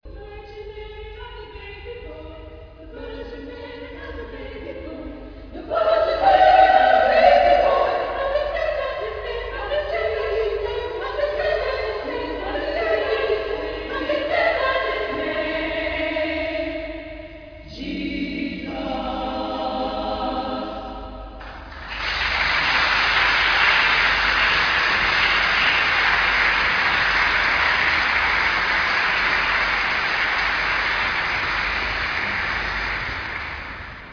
The Cecilian Singers have provided musical entertainment of the highest quality - classical, sacred and secular works.
24-voice group
Listen to the choir (Real Audio)